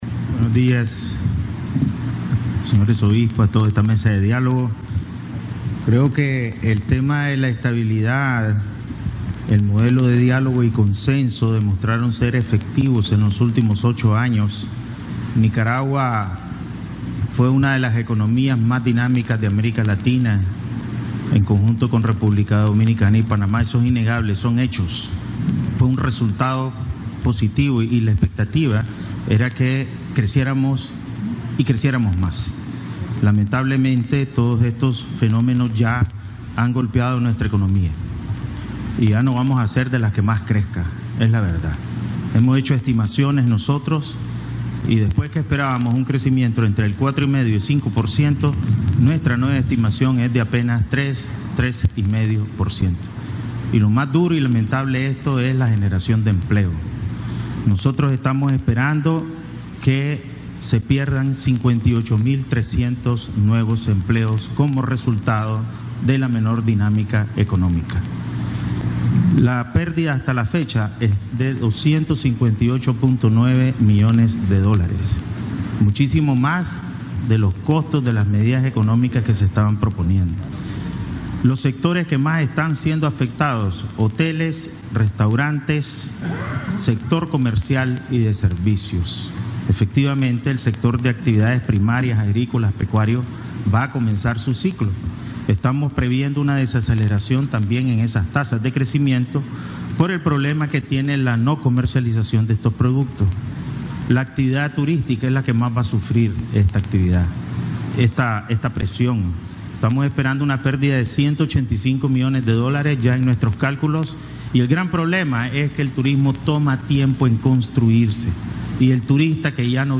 Presidente del BCN Cro. Ovidio Reyes R. señala situación de economía en Diálogo Nacional
Audio: Declaraciones del Presidente del BCN, Cro. Ovidio Reyes R. (amr /